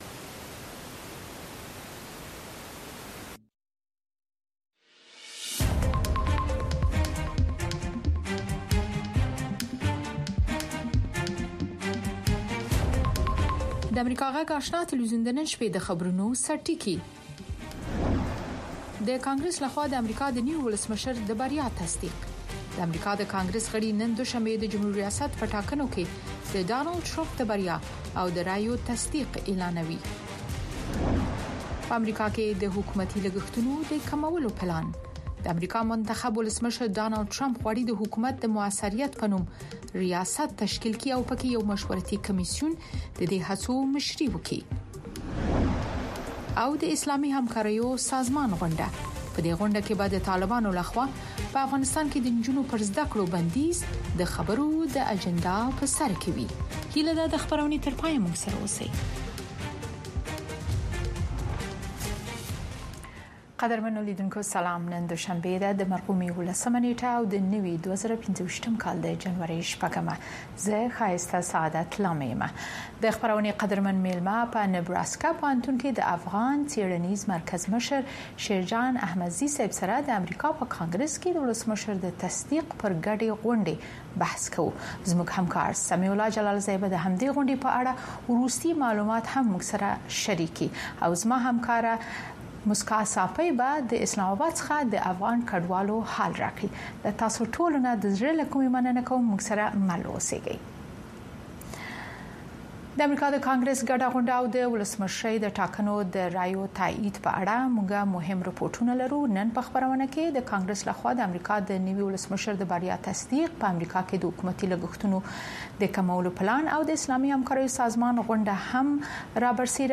د اشنا په خبري خپرونو کې د شنبې څخه تر پنجشنبې پورې د افغانستان، سیمې او نړۍ تازه خبرونه، او د ټولې نړۍ څخه په زړه پورې او معلوماتي رپوټونه، د مسولینو او کارپوهانو مرکې، ستاسې غږ او نور مطالب د امریکاغږ راډیو، سپوږمکۍ او ډیجیټلي شبکو څخه لیدلی او اوریدلی شی.